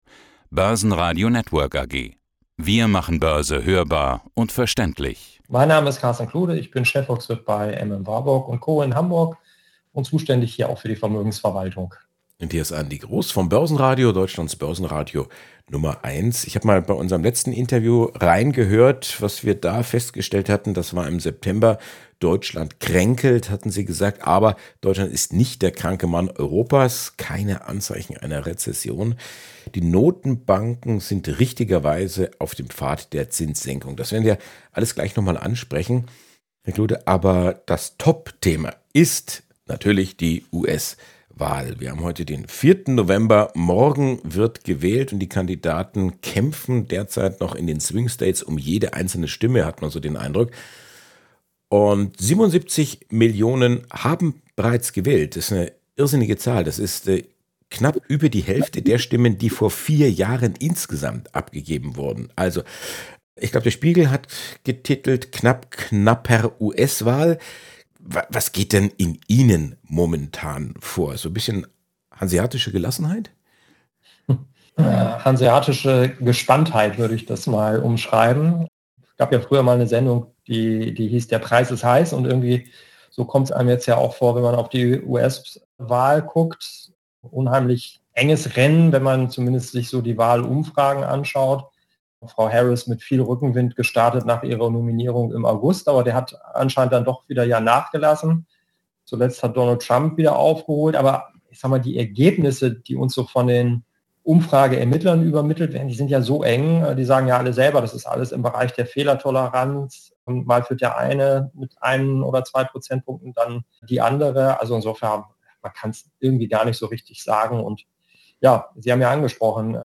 Downloads Zum Interview